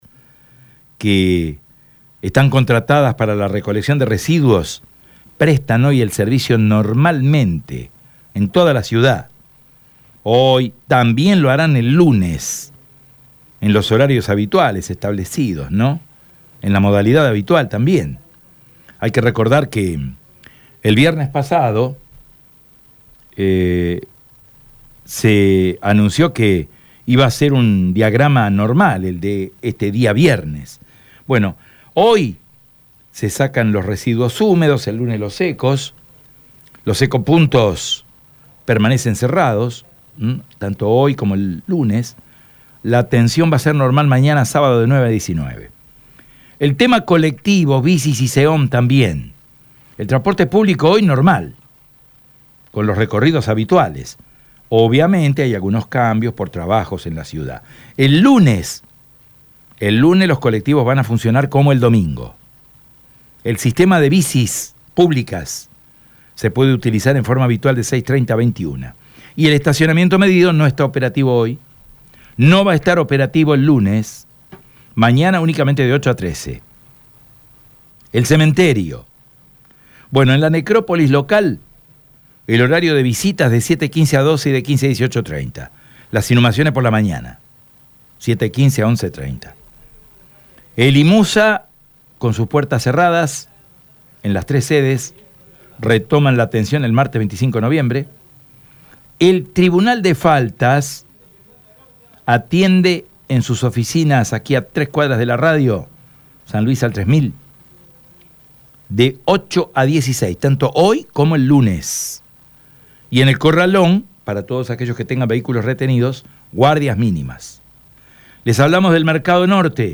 El abogado laboralista y exministro de Trabajo, Juan Manuel Pusineri, analizó los datos en diálogo con EME.